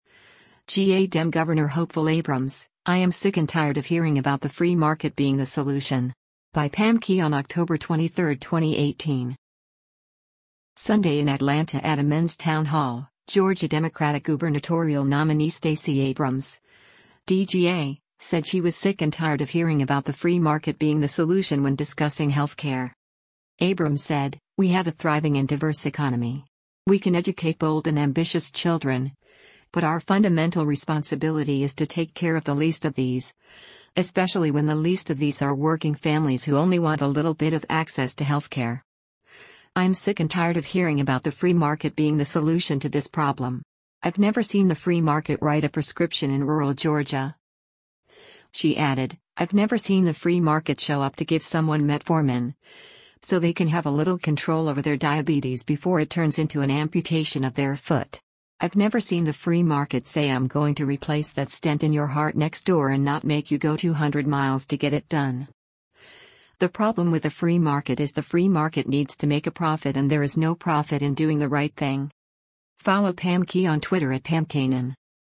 Sunday in Atlanta at a  “Men’s town hall,” Georgia Democratic gubernatorial nominee Stacey Abrams (D-GA) said she was “sick and tired of hearing about the free market being the solution” when discussing health care.